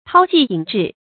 韜跡隱智 注音： ㄊㄠ ㄐㄧˋ ㄧㄣˇ ㄓㄧˋ 讀音讀法： 意思解釋： 謂藏匿蹤跡，不露才智。